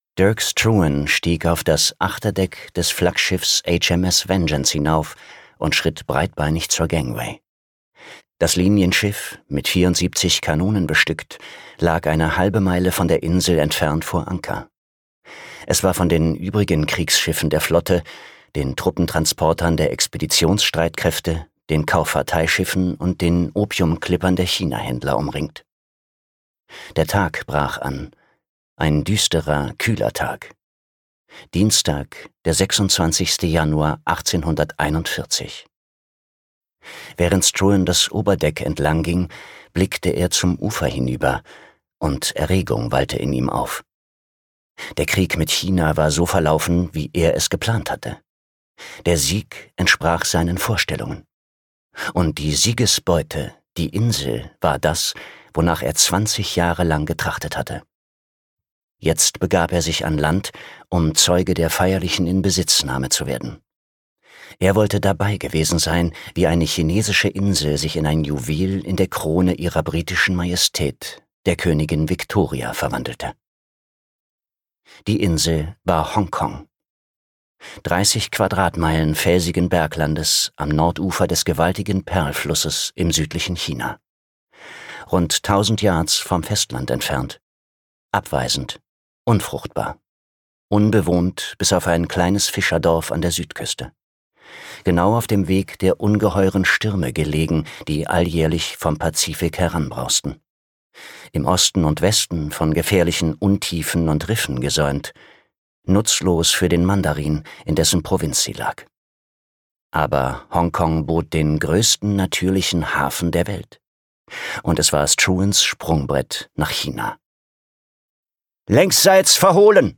Das epische Hörbuch über die Gründung Hongkongs vom Großmeister des historischen Romans, James Clavell
Gekürzt Autorisierte, d.h. von Autor:innen und / oder Verlagen freigegebene, bearbeitete Fassung.